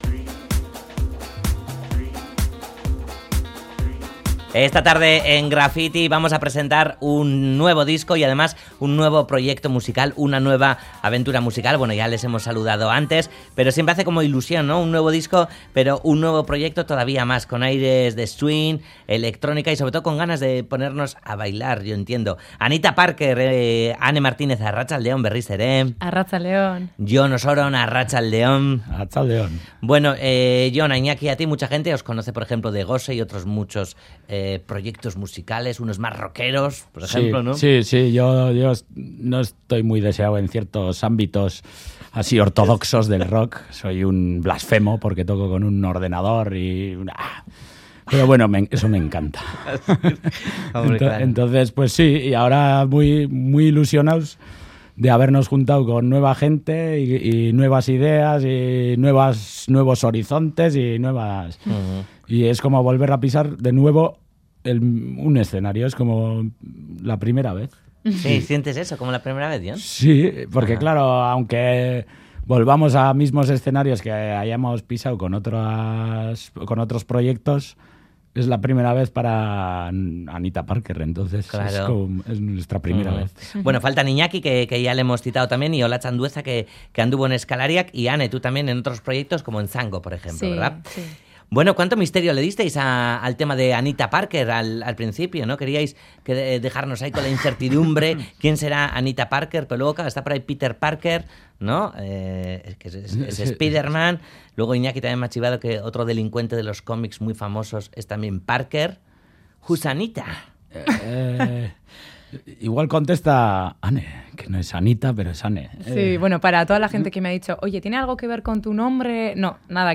Audio: Presentan las primeras canciones del grupo centradas en el electro-swing pero que no olvidan los guiños al pop o el tango